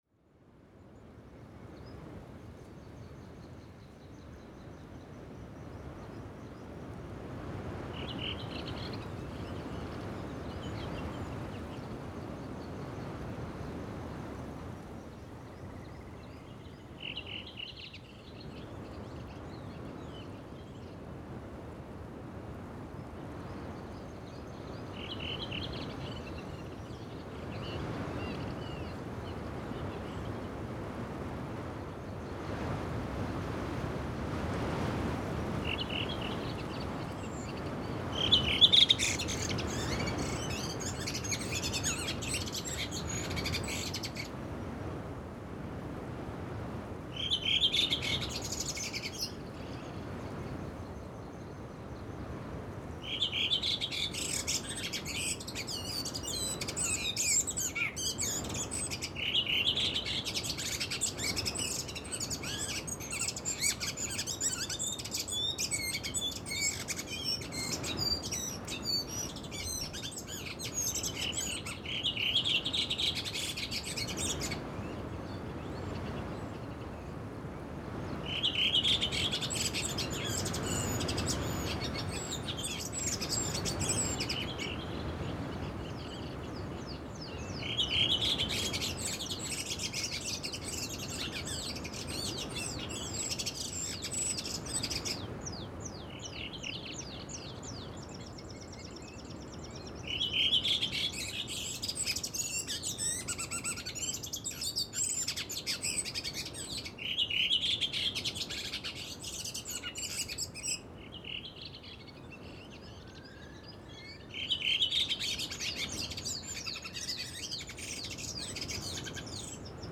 Here is a recording that was left to run for a whole summer night, 29th of June, 2023.
Here is one recording that I recorded at a farm that I have access to. This night I decided to use the NT1 beneath a house wall to have a shalter against the wind.
It is dawn and you should hear a lot of birdsong. But the wind drowns out all the birdsong. But then the wind starts to calm down and you can hear more birdsong and bird actictivity, both near and far.